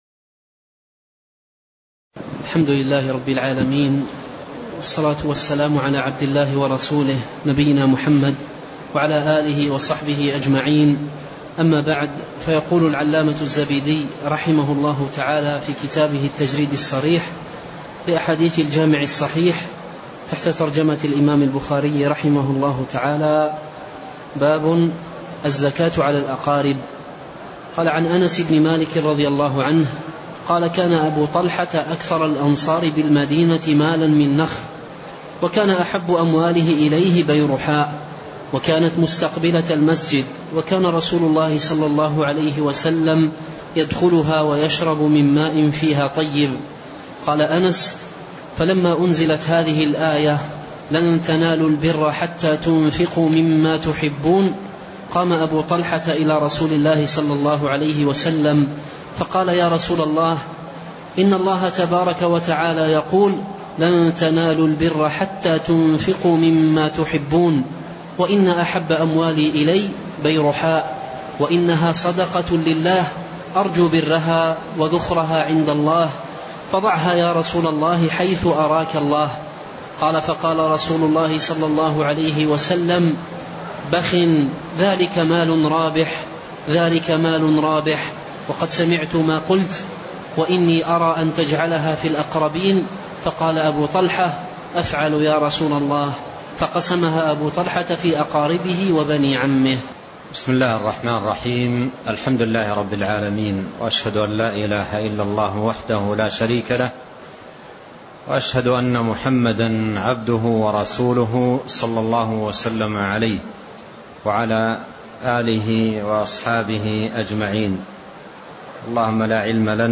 تاريخ النشر ٢٠ جمادى الآخرة ١٤٣٤ هـ المكان: المسجد النبوي الشيخ